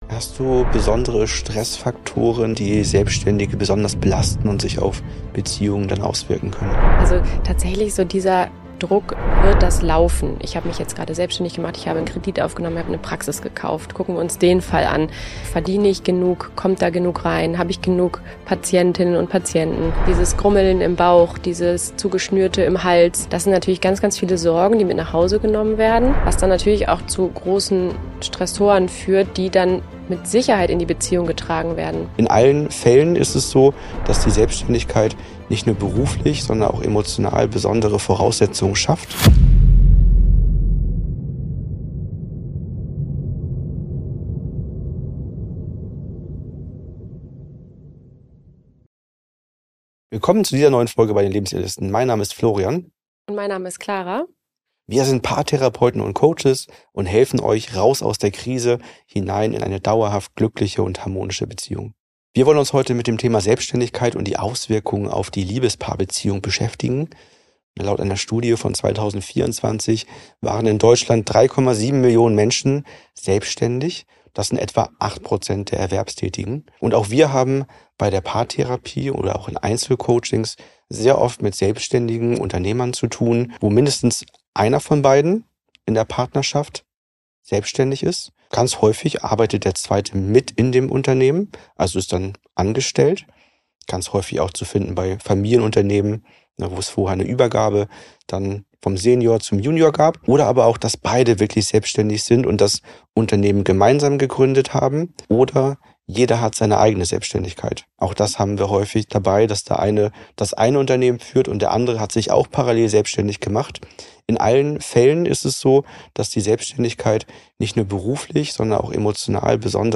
Beziehungskiller Selbstständigkeit? So kannst du für deinen Anteil Verantwortung übernehmen! - Interview